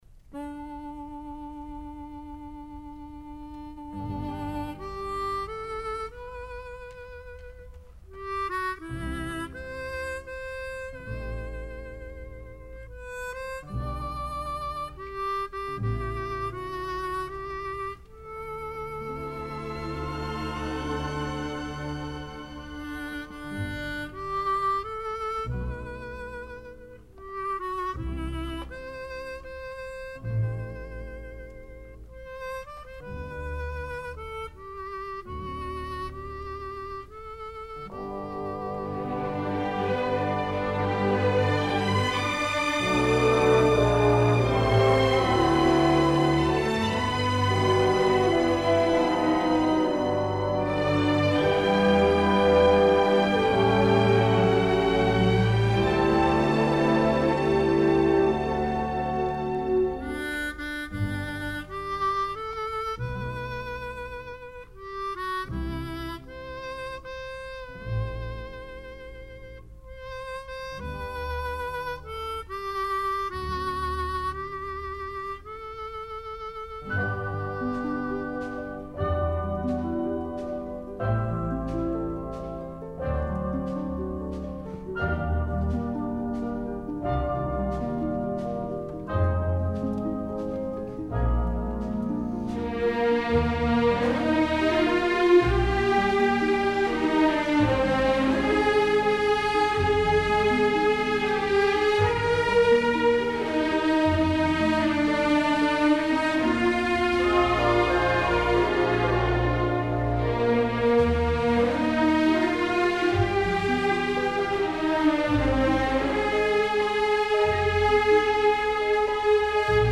洒脱而温柔多情的的骑士英姿，节奏明快让人热血沸腾，而慢板弦乐如丝绸般轻轻飘出，让人感到无比的温馨。